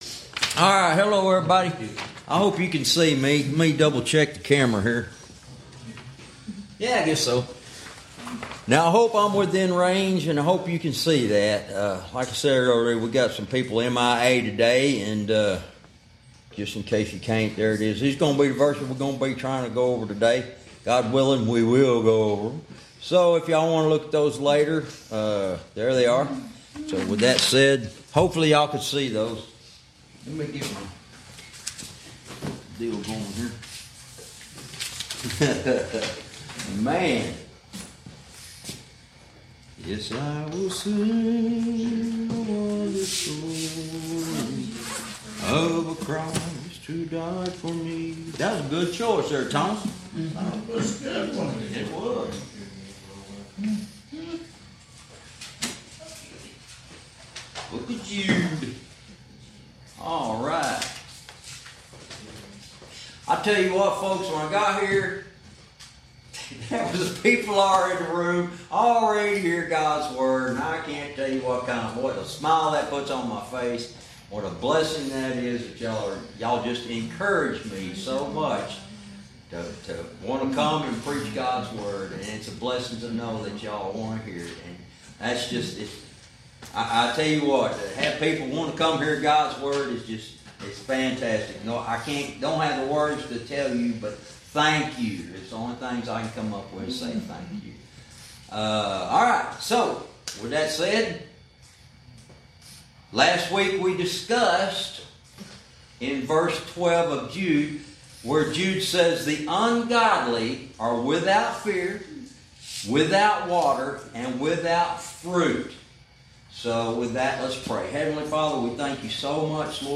Verse by verse teaching - Lesson 53 verse 13